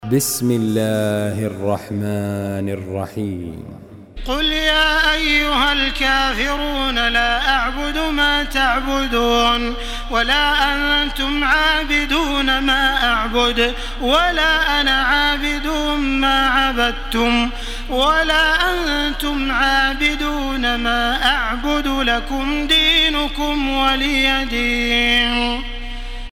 Surah Kafirun MP3 by Makkah Taraweeh 1434 in Hafs An Asim narration.